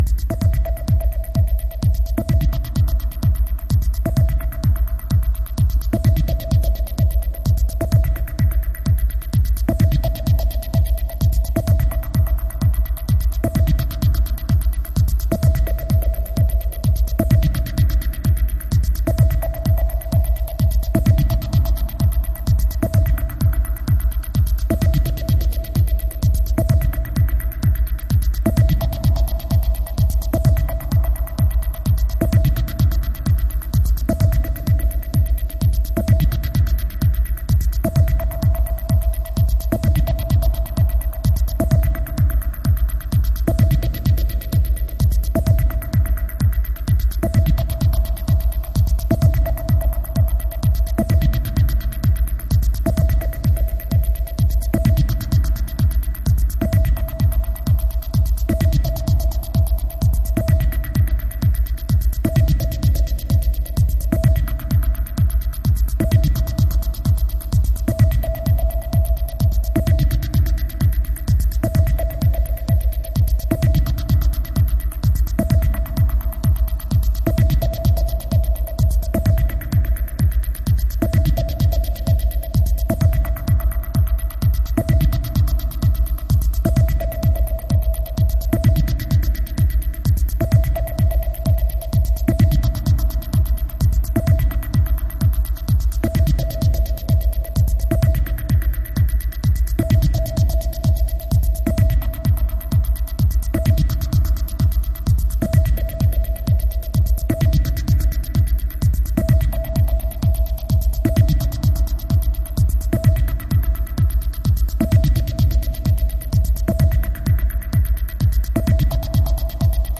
ほぼアナログ機材、ライブ録音で制作される実験ミニマル作品。